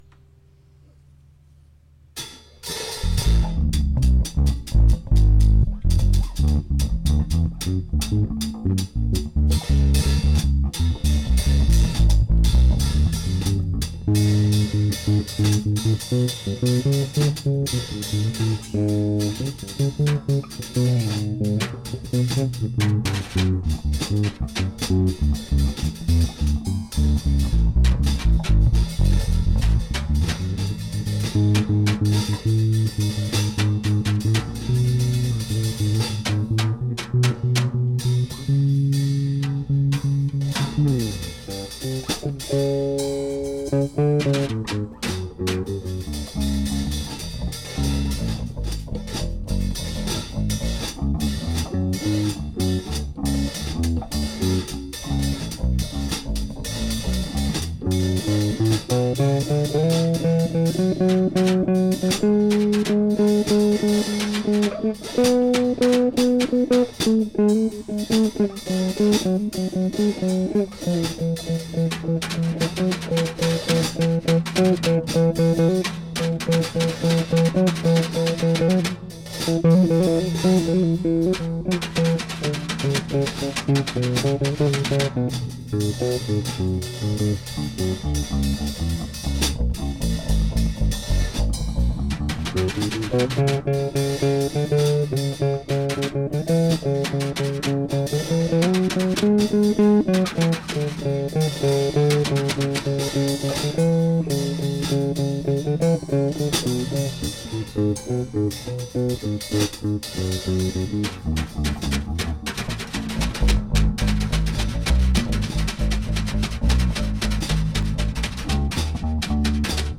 Jazz Improv...experimental.. etc....practice sessions...
bass, guitar..
percussion